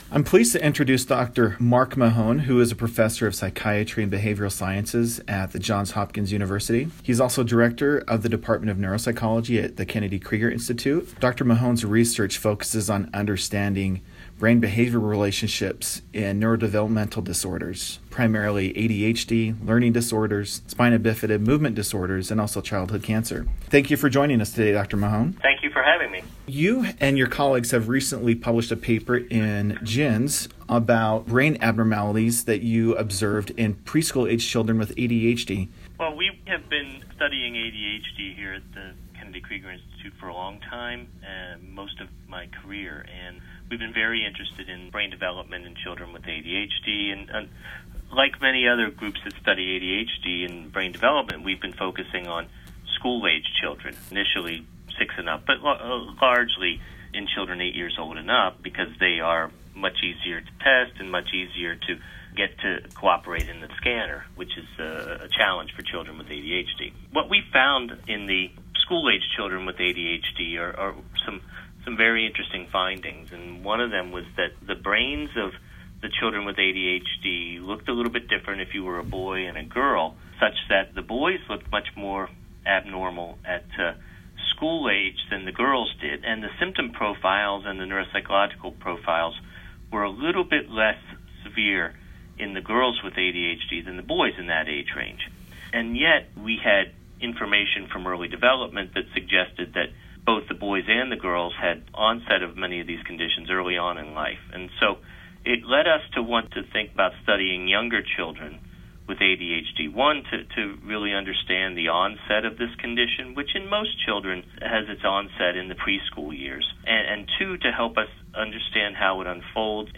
Brain Imaging in Preschool-Aged Children Reveals Potential Markers of ADHD: An Interview